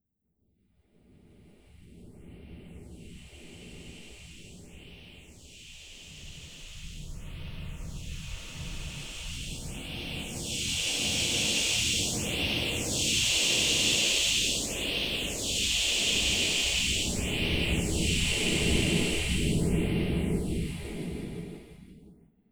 I am more concerned with impercetible sounds in my recordings and this was my focus during the workshop, although I did record some sounds in the stairwell and then some smaller sounds.
ste-039-zzzzz-nr-phaser-bim.wav